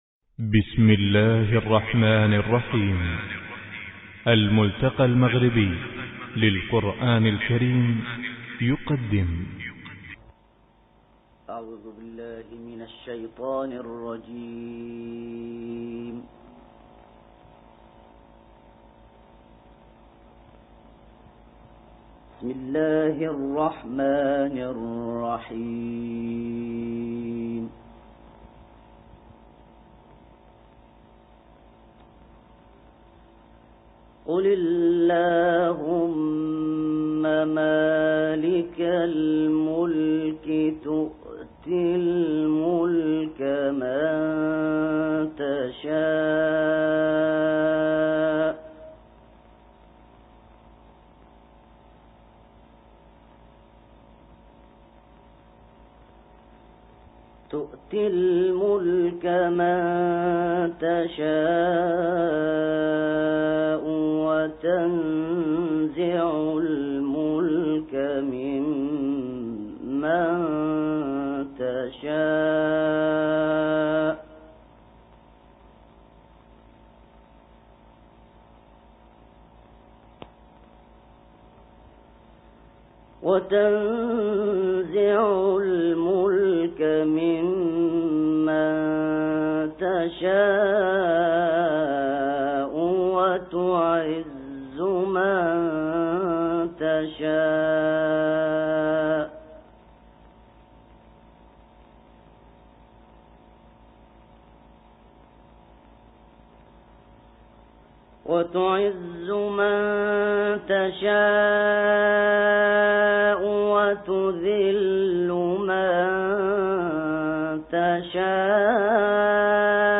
سه تلاوت متفاوت